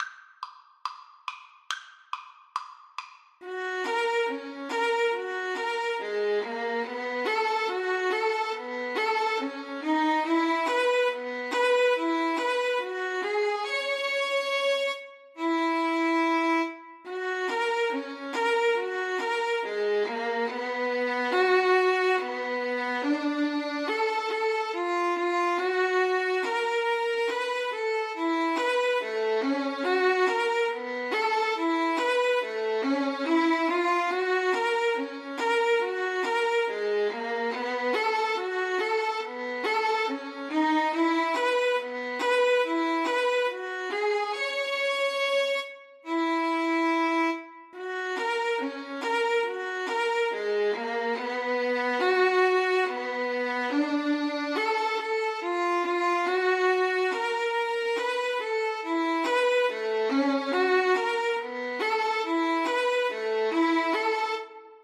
Violin 1Violin 2
4/4 (View more 4/4 Music)
Traditional (View more Traditional Violin Duet Music)
Rock and pop (View more Rock and pop Violin Duet Music)